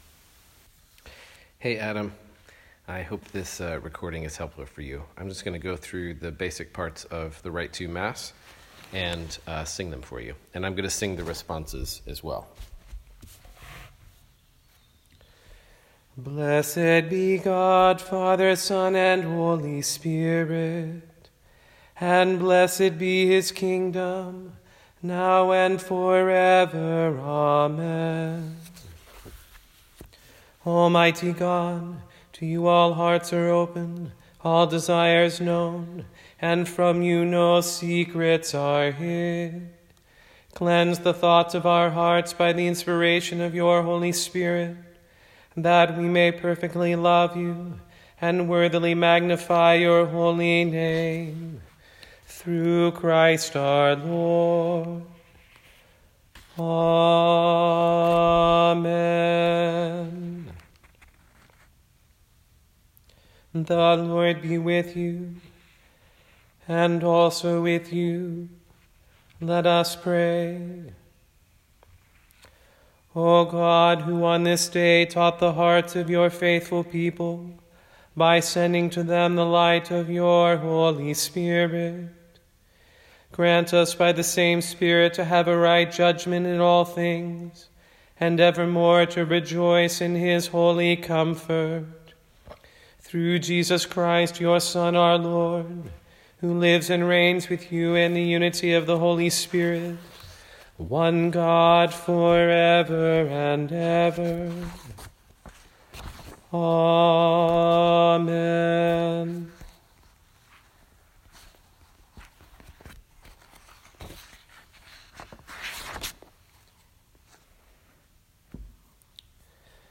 Rite II, recording of the sung parts of mass for the priest